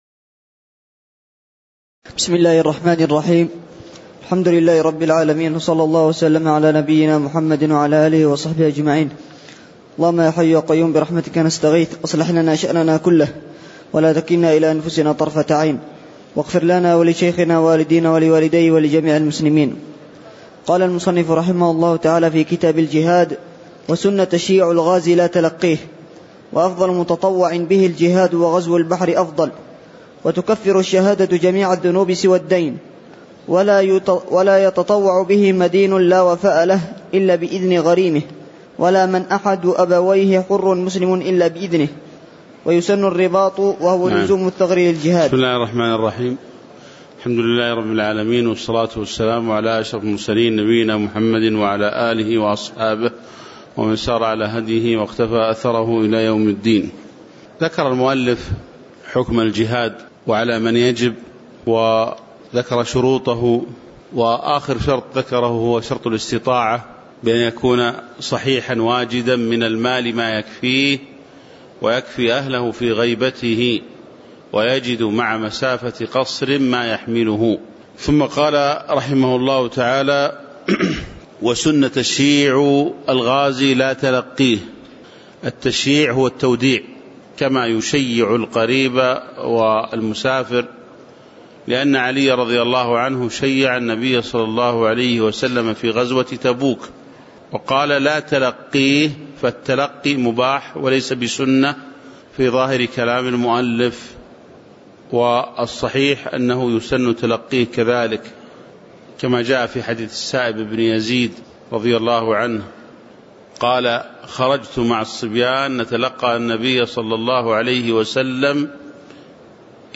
تاريخ النشر ٩ ذو القعدة ١٤٤٠ هـ المكان: المسجد النبوي الشيخ